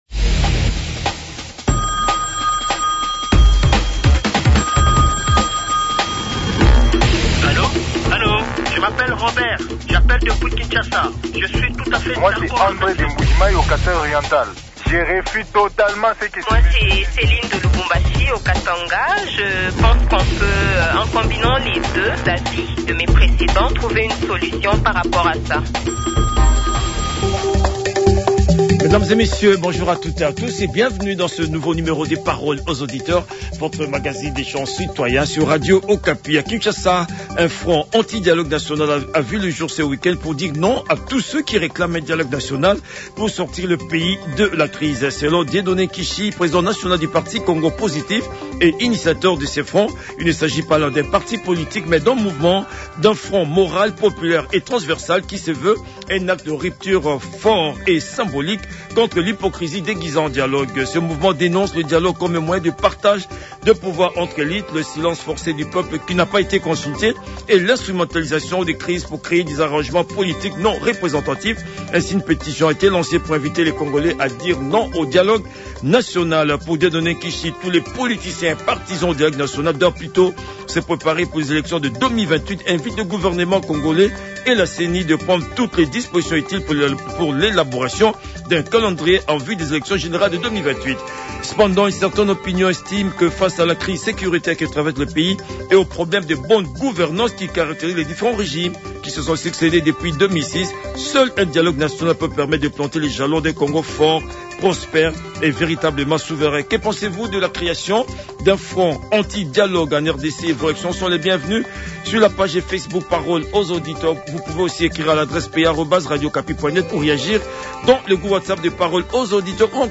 Les auditeurs ont débattu